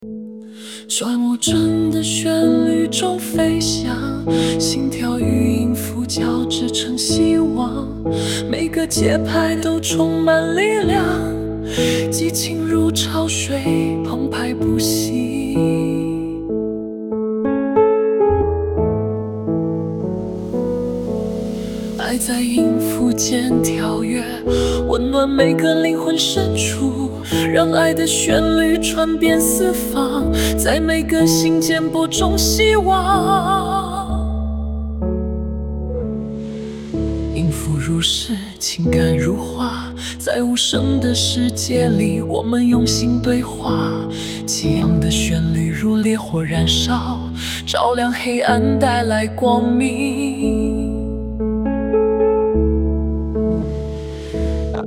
Prompt：请创作一首充满激情充满爱心的纯音乐
人工智能生成式歌曲